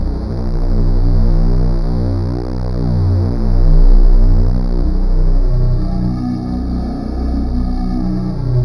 Index of /90_sSampleCDs/Club_Techno/Atmos
Atmos_01_B1.wav